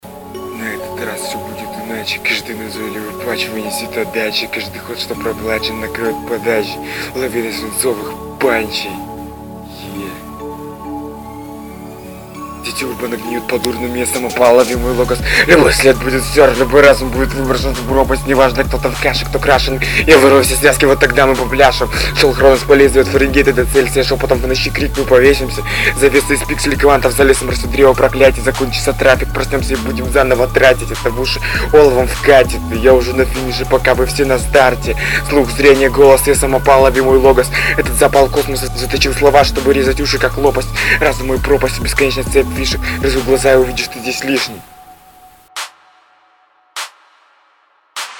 Нет дикции